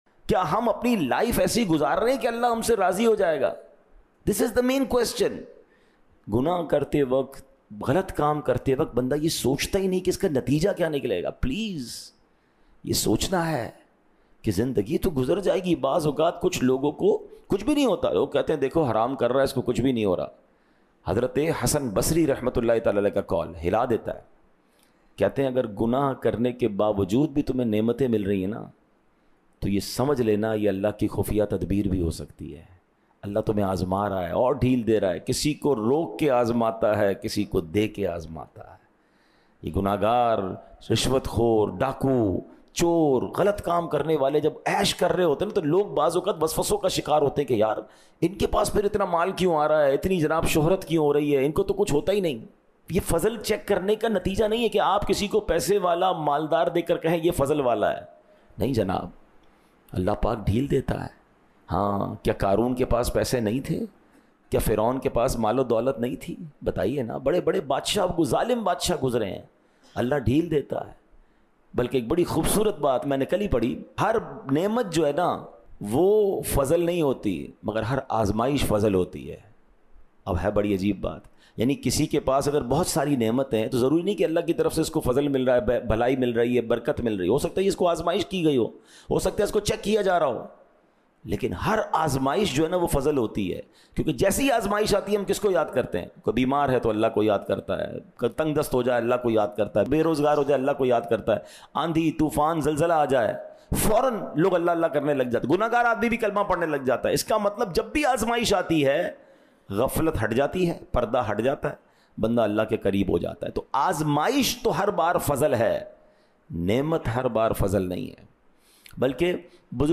khutba